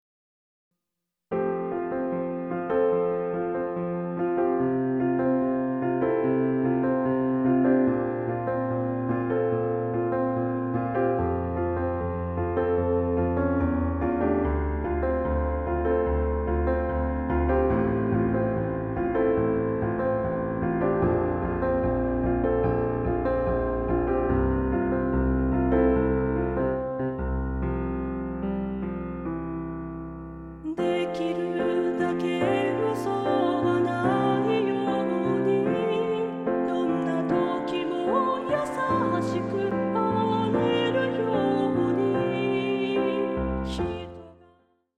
合唱パート練習CD
混声3部合唱／伴奏：ピアノ